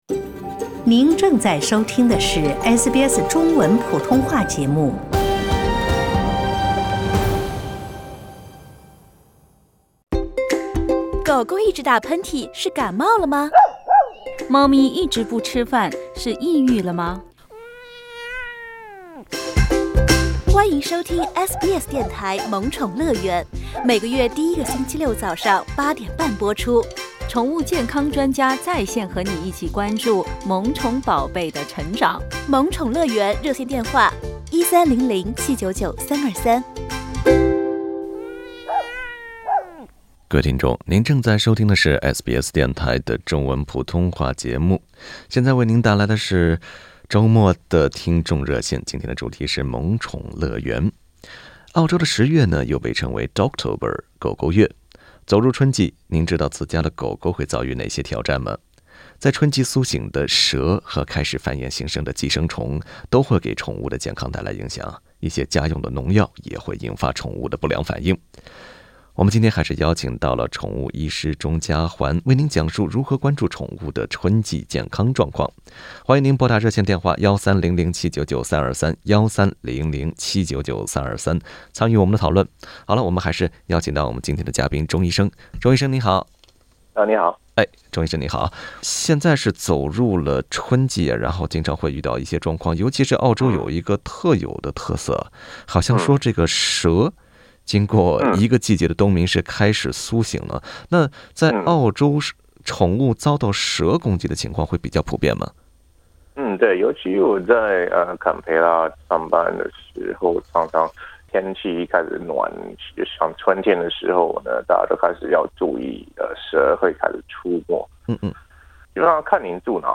a dog Source: Pixabay 一些听众也就春季的宠物问题进行提问。